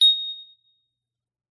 notification-sound-effect.mp3